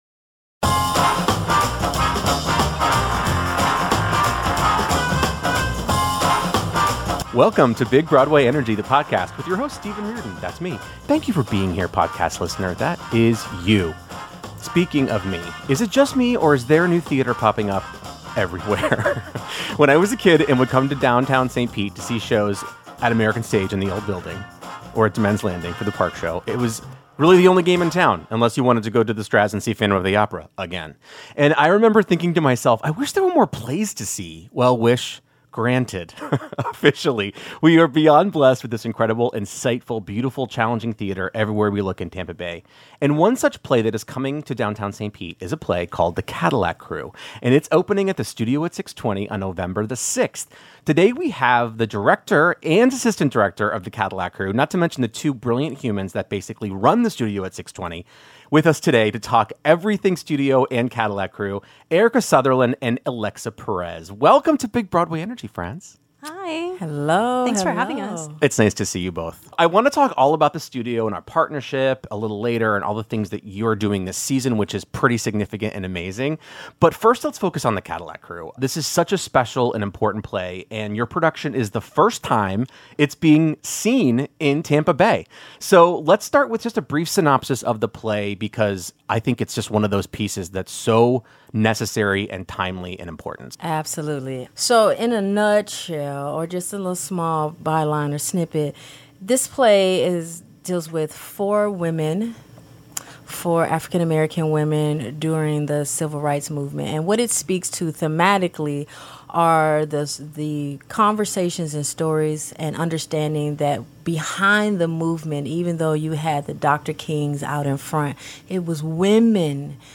The conversation delves into the real-life “Cadillac crews” — interracial teams of women who drove through the segregated South to advance civil rights — and discusses how art, history, and activism intersect in this vital new production.